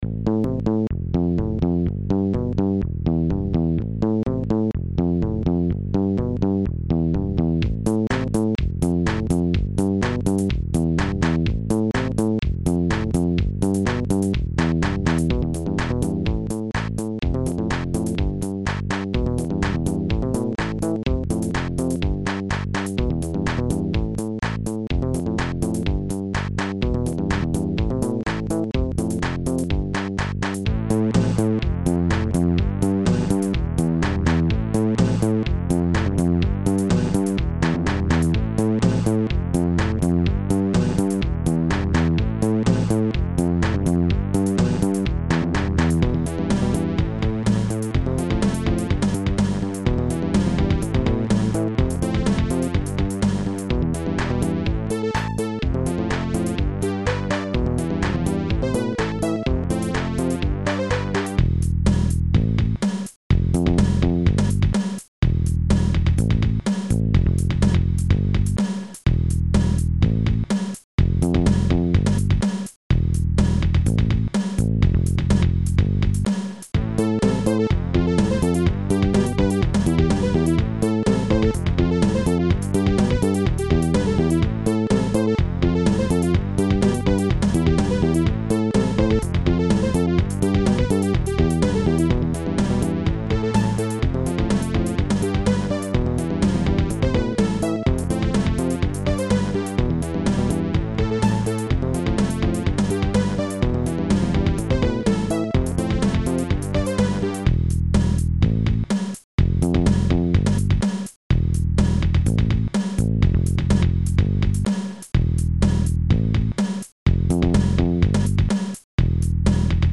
These songs were created using the SoundBlaster music tracker software on my Commodore Amiga computer.